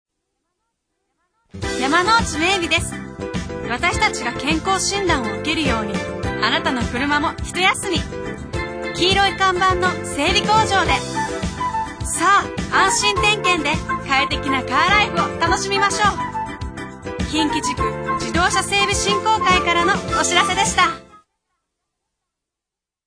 ラジオＣＭは、ＭＢＳ（毎日放送ラジオ）にて９月から11月の３ヶ月間２つの番組で20秒ＣＭを番組提供で放送します。